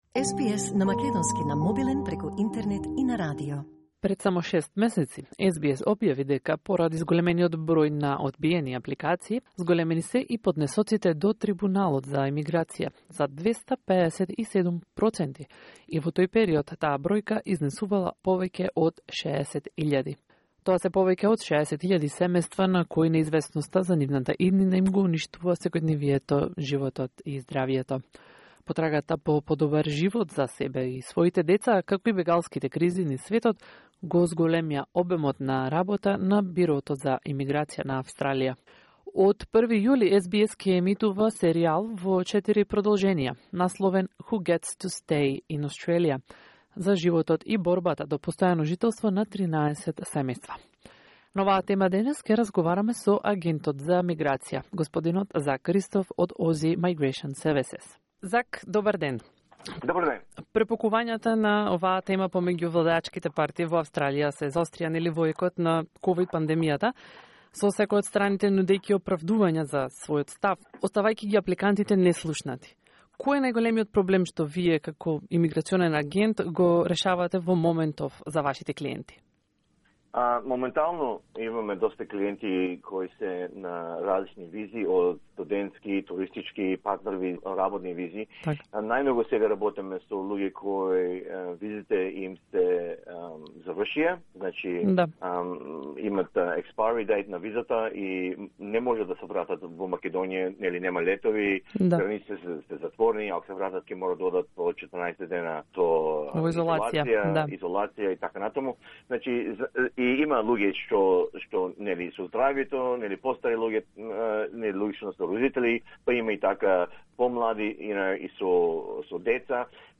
The search for a better life for themselves and their children, as well as the refugee crisis around the world, has increased the workload of the Australian Immigration Bureau. Interview